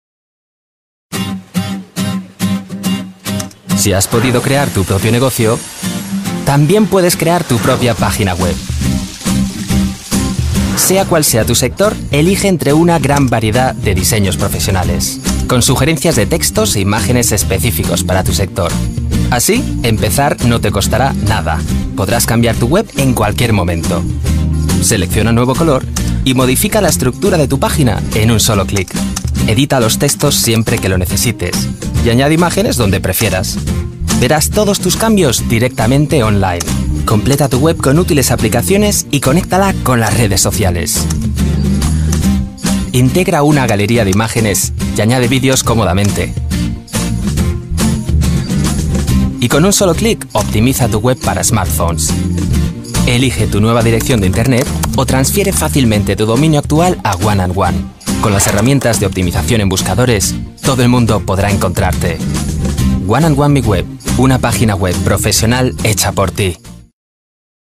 Spanischer Sprecher (Native Speaker aus Madrid) und Synchronschauspieler, junge, frische und dynamische Stimme.
kastilisch
Sprechprobe: Sonstiges (Muttersprache):
Versatile voice artist, Spanish native speaker, for all kind of productions and shows, voice-overs, advertisements, radio plays, dubbing, audio books, audio guides, image films...